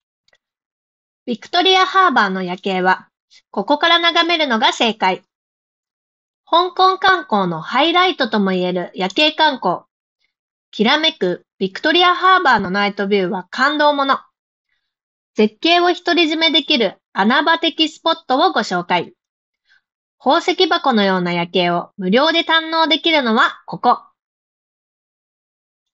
こちらのノイキャンも質が高く、周囲のノイズを取り除き、装着者の声のみをクリアに拾い上げることができていた。
聴いても分かる通り、そこそこの品質の独立型マイクで収音した場合と同程度のクオリティで、音声を拾い上げることができている。
▼REDMI Buds 6 Proの内蔵マイクで拾った音声単体